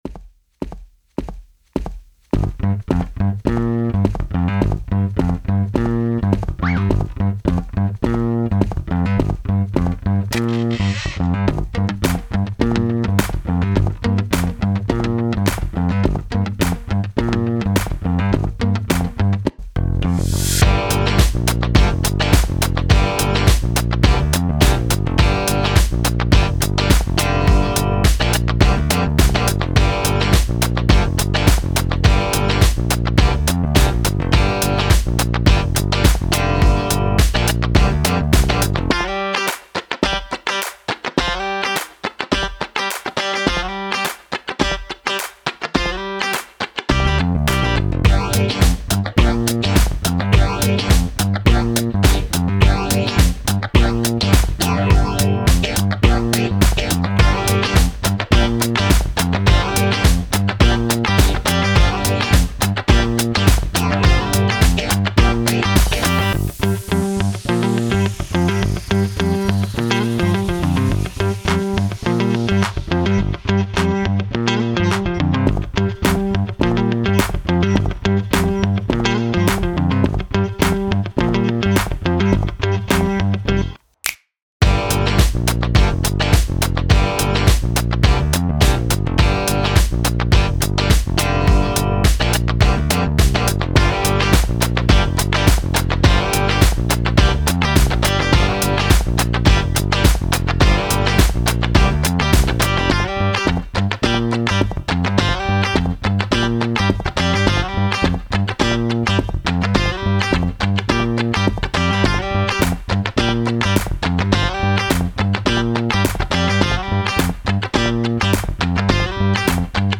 タグ: おしゃれ かっこいい 明るい/楽しい コメント: スタイリッシュに都会を歩くシーンをイメージした楽曲。